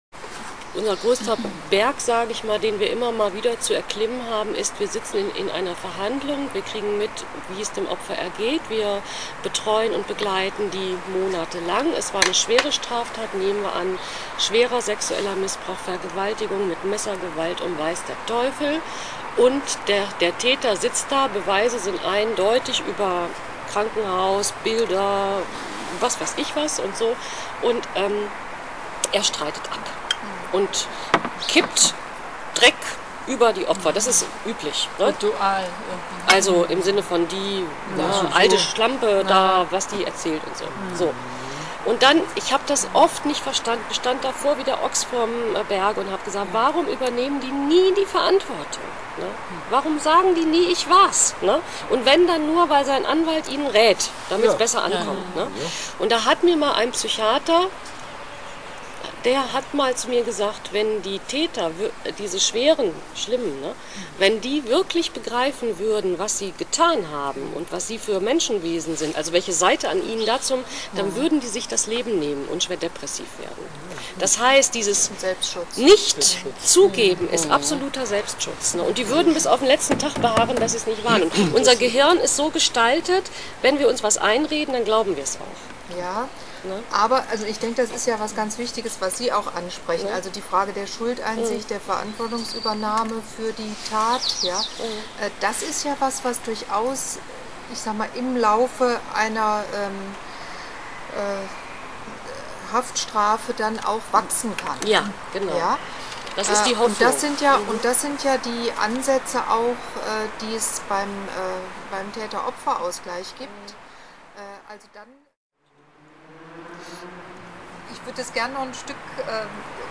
Das Gespräch fand bei Kaffee und Kuchen (passend aus der JVA Kassel-Wehlheiden) im Seitenhof der Kasseler Elisabethkirche statt.